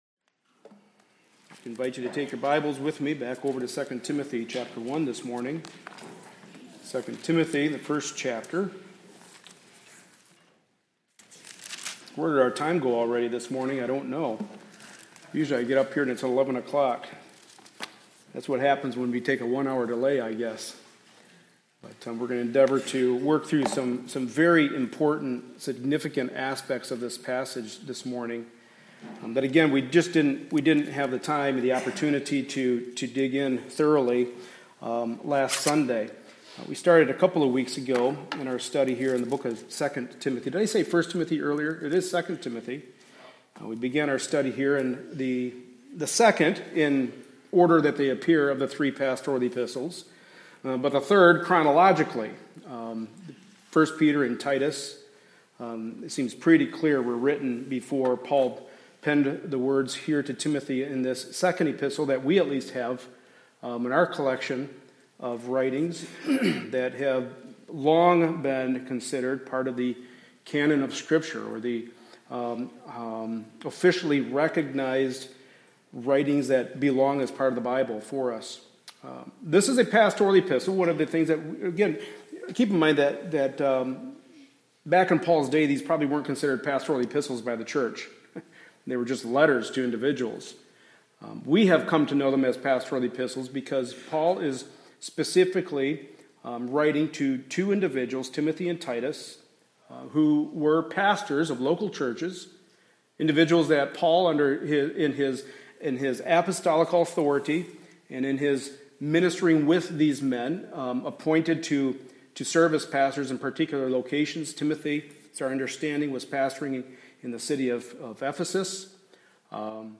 2 Timothy 1:12-14 Service Type: Sunday Morning Service A study in the Pastoral Epistles.